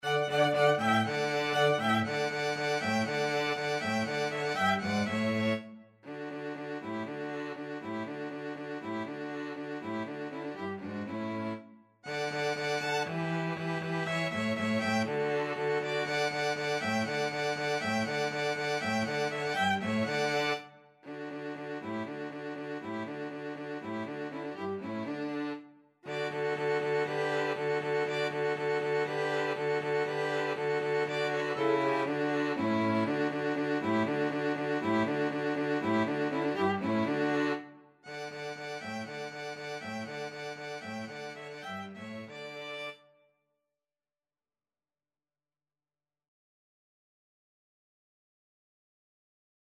Free Sheet music for 2-Violins-Cello
Violin 1Violin 2Cello
D major (Sounding Pitch) (View more D major Music for 2-Violins-Cello )
Allegro (View more music marked Allegro)
4/4 (View more 4/4 Music)
Classical (View more Classical 2-Violins-Cello Music)